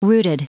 Prononciation du mot rooted en anglais (fichier audio)
Prononciation du mot : rooted